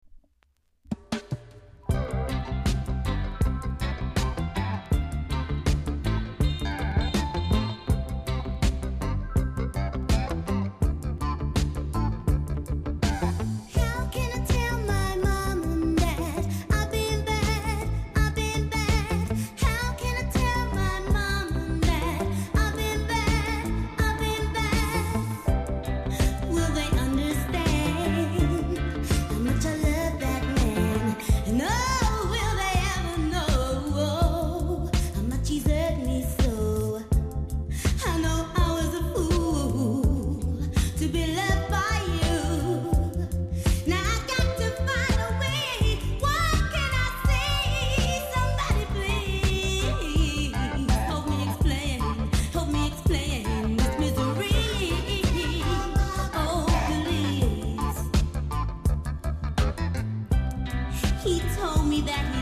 後半で少しプチパチあります。